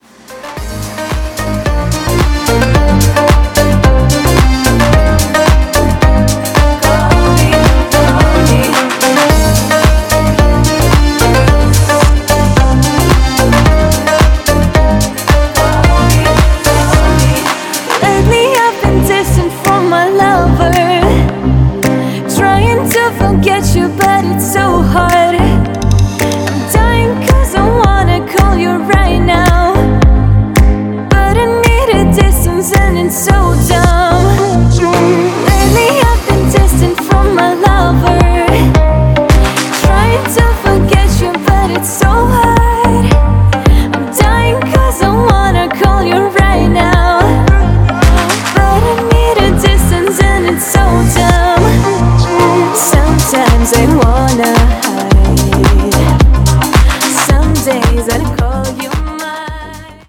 • Качество: 320, Stereo
deep house
восточные мотивы
атмосферные
мелодичные
Electronic
красивый женский голос
теплые
Стиль: deep house.